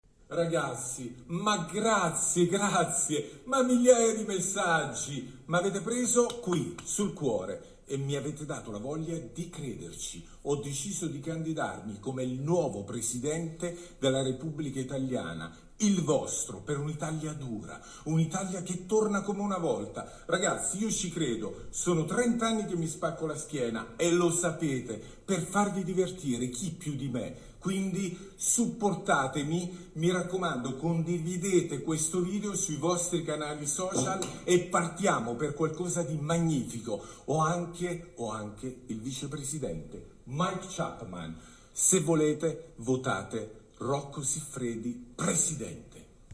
Ed ecco cosa ha detto Rocco (ve lo proponiamo in audio)